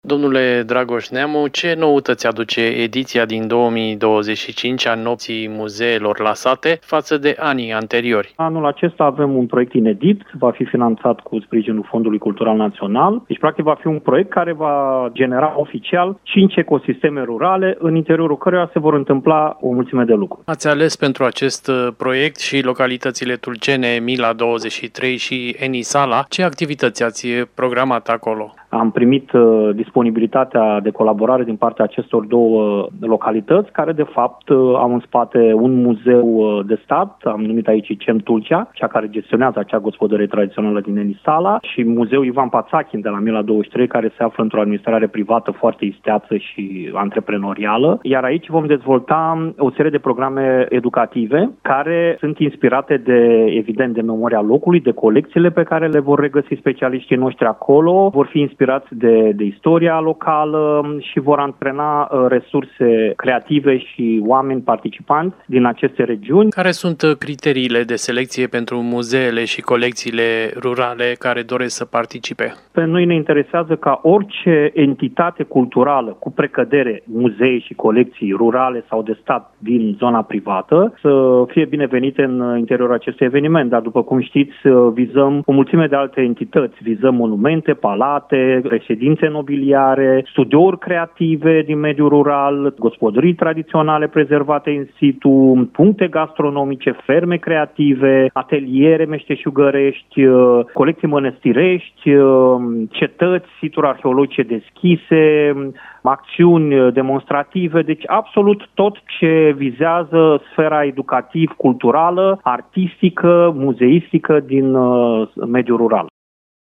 Mai multe detalii, în interviul